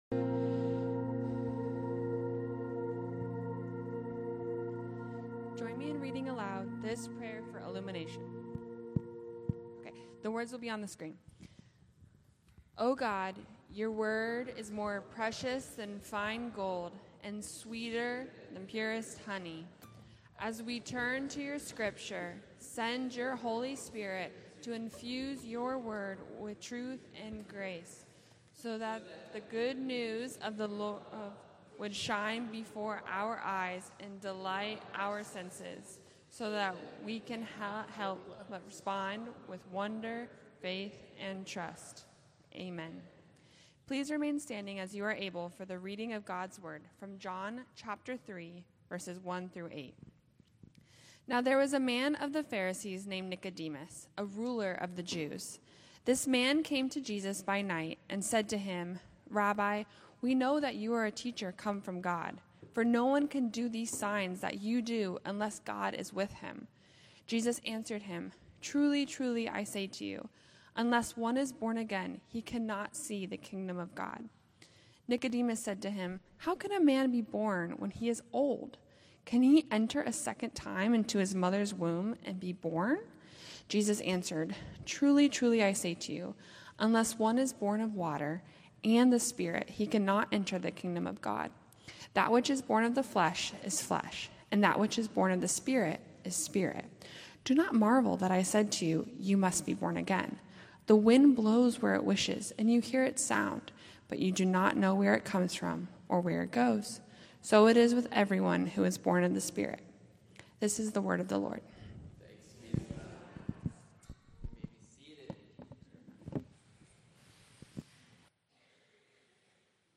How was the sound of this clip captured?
In this message from John 3:1-21, discover how Jesus’ conversation with Nicodemus reveals the truth that real life begins with new life in Him. Listen as Eastpoint Church unpacks what it means to be born again and to live fully through the gospel.